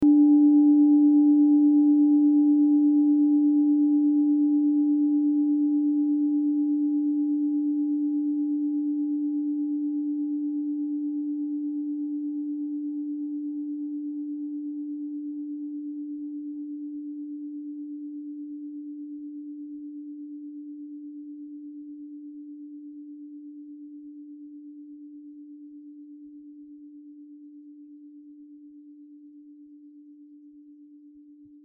Klangschalen-Typ: Bengalen und Tibet
Klangschale 6 im Set 4
Durchmesser = 20,8cm
(Aufgenommen mit dem Filzklöppel/Gummischlegel)
klangschale-set-4-6.mp3